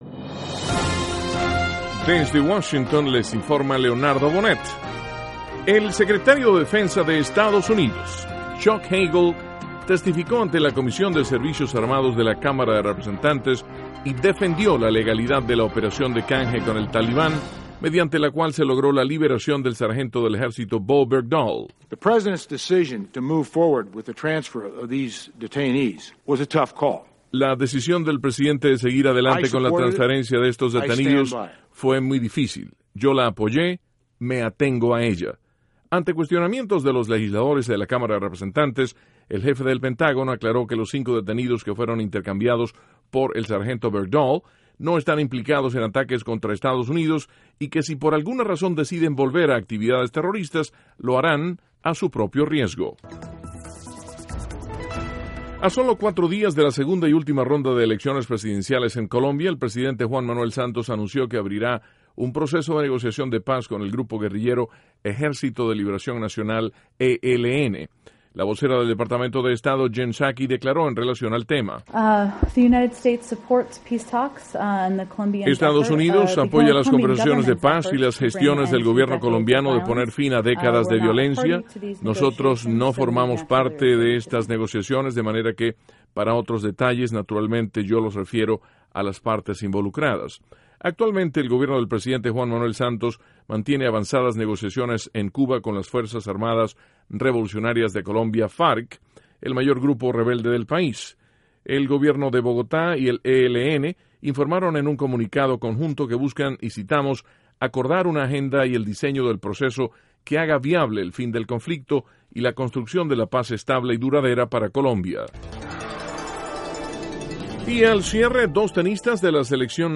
NOTICIAS - MIÉRCOLES, 11 DE JUNIO, 2014